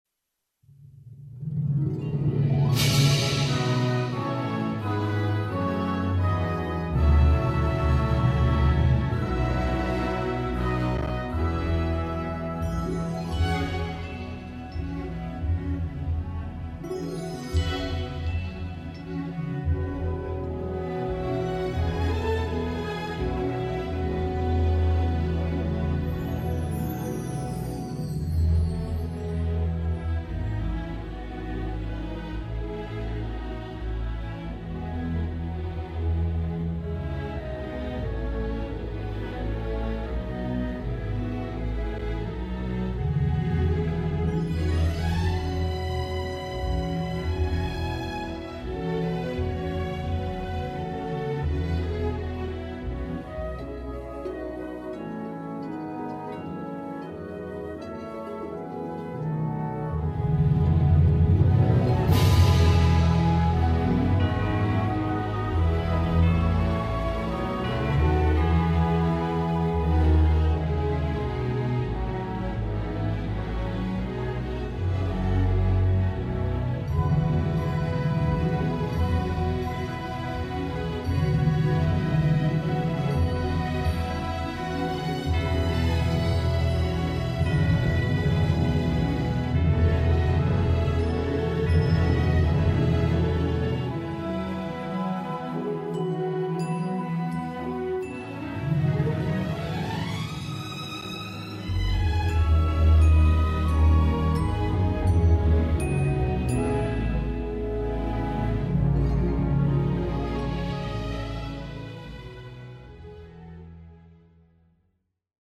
Low Key without BV